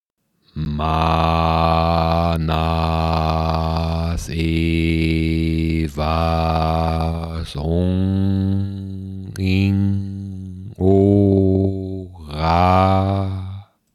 Ausgesprochen wird der KraftKlang wie folgt:
Hier kannst Du Dir anhören wie der Kraftklang richtig "ausgesprochen" wird.